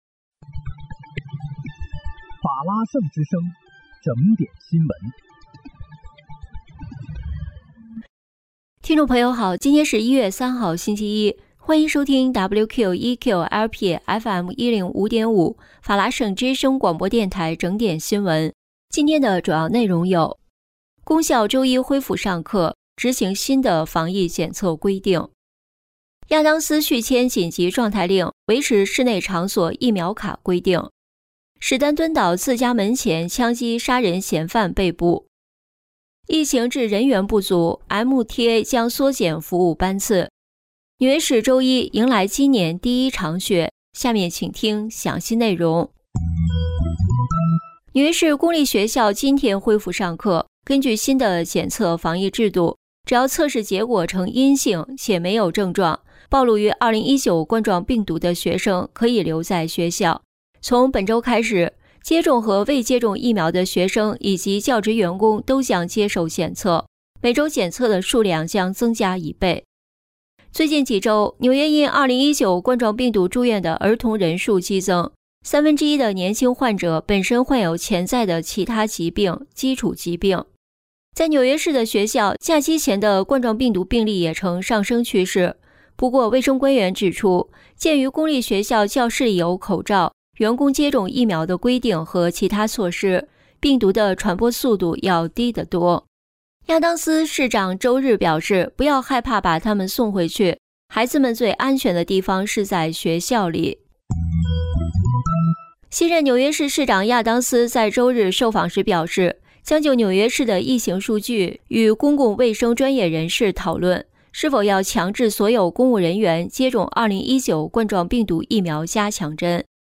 1月3日(星期一）纽约整点新闻